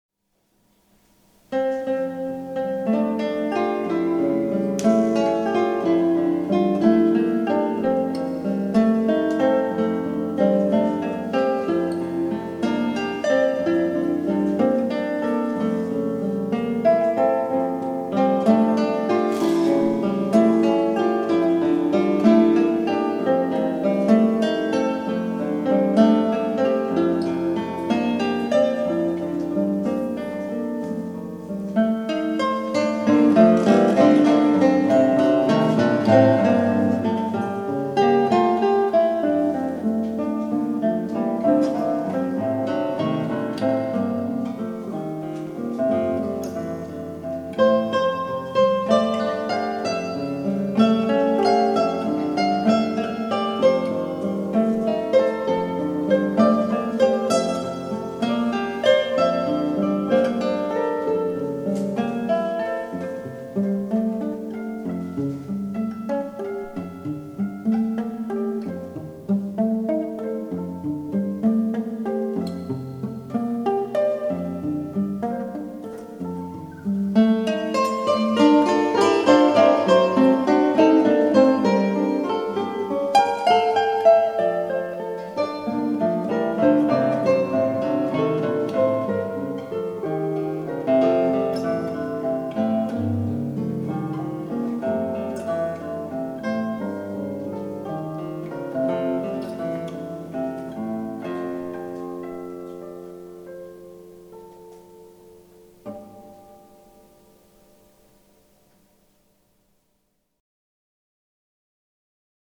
for 3 guitars | per 3 chitarre